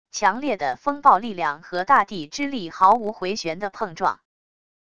强烈的风暴力量和大地之力毫无回旋的碰撞wav音频